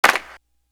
Infamous Clap.wav